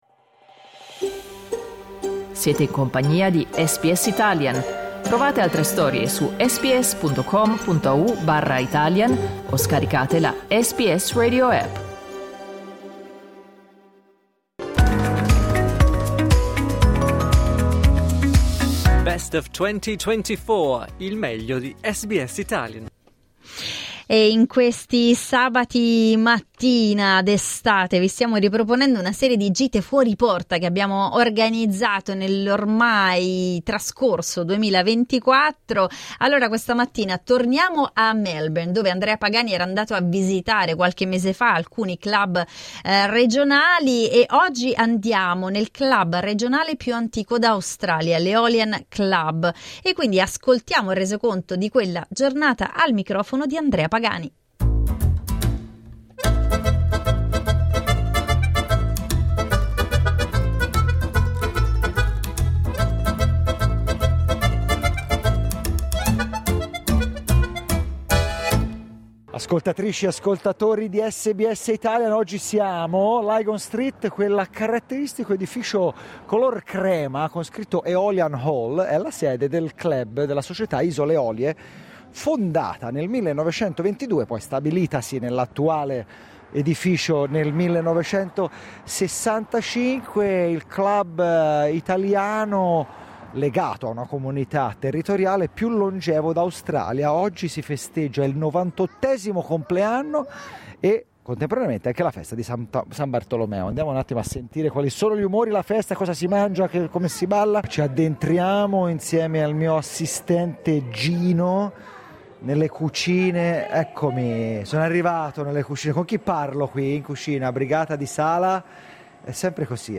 Dai nostri archivi, la visita alla sede della Societò Isole Eolie a Brunswick, attiva da 99 anni.